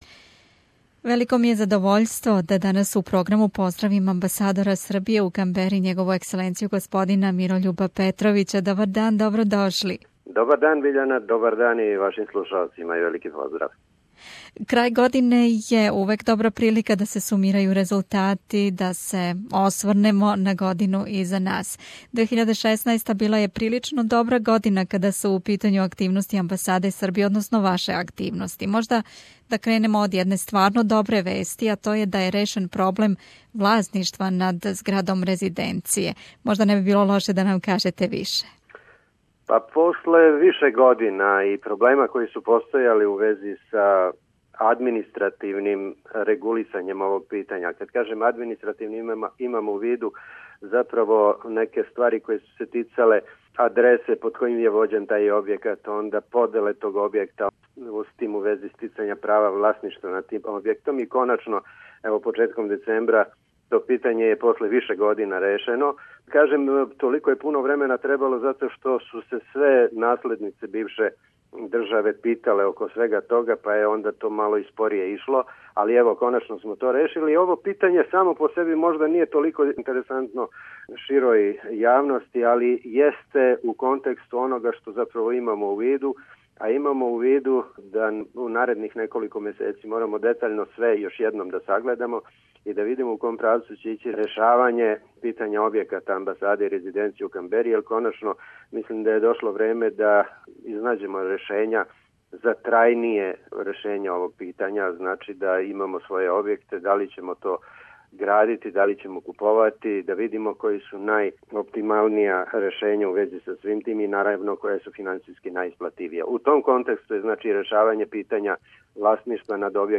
У разговору са амбасадором Србије у Камбери Његовом екселенцијом господином Мирољубом Петровићем осврнули смо се на годину из нас и на све важне активности амбасаде током протелких 12 месеци.